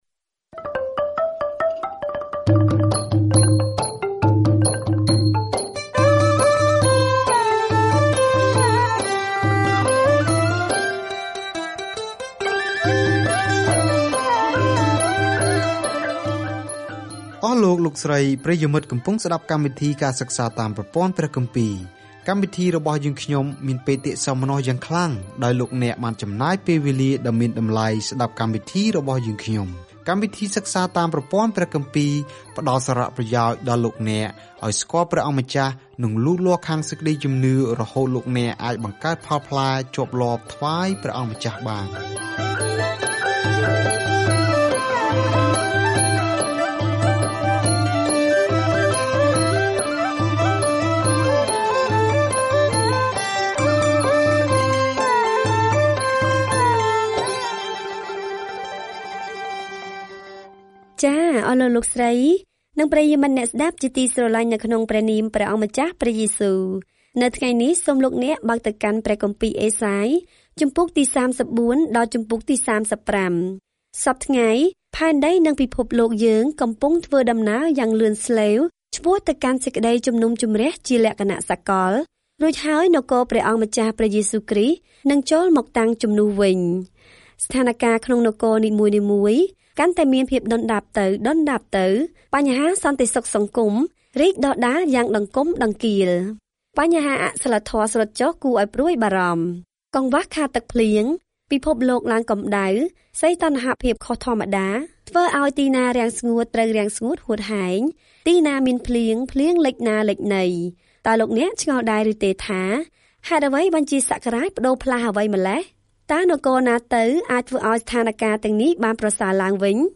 ការធ្វើដំណើរជារៀងរាល់ថ្ងៃតាមរយៈអេសាយ ពេលអ្នកស្តាប់ការសិក្សាជាសំឡេង ហើយអានខគម្ពីរដែលបានជ្រើសរើសពីព្រះបន្ទូលរបស់ព្រះ។